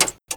Systems: Add new custom Fuel System Systems: Fix some minor issues Sound: Add Sounds for Battery and External Power Relays 2017-06-09 12:53:49 -04:00 35 KiB Raw History
relay2.wav